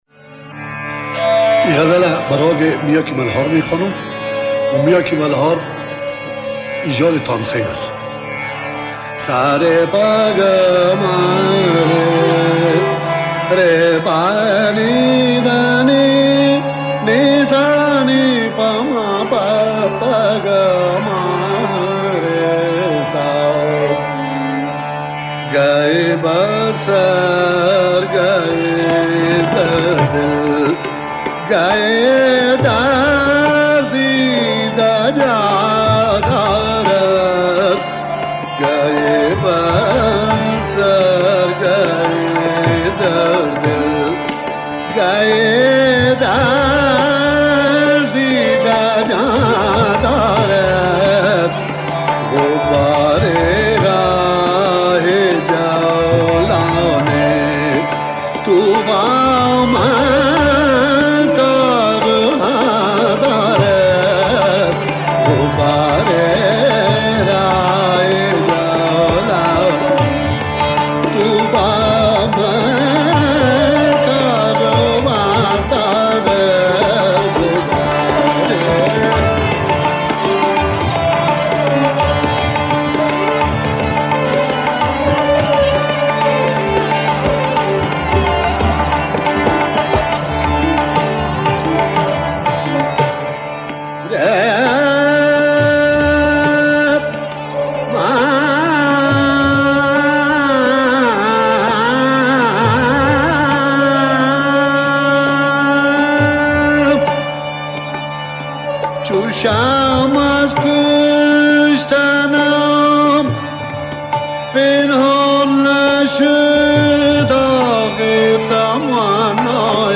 [در ابتدای آهنگ توضیحی دربارهٔ راگ آن داده می‌شود.]